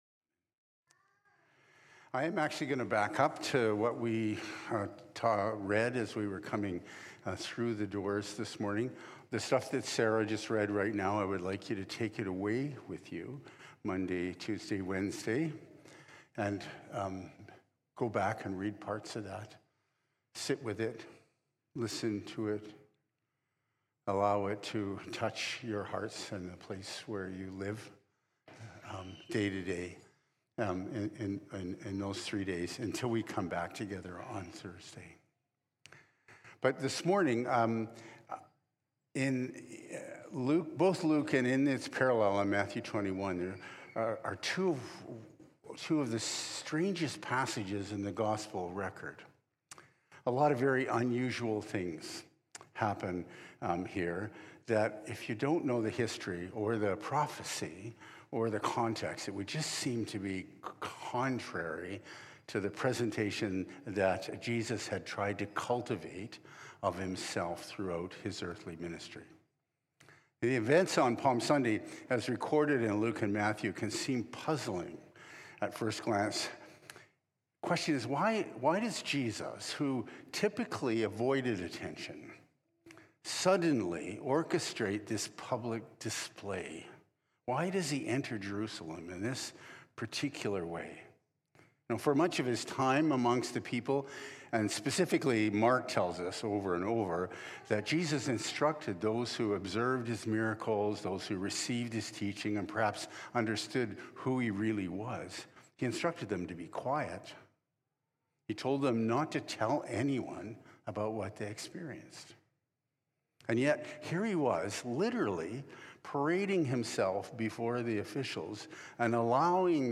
Sermons | Emmaus Road Anglican Church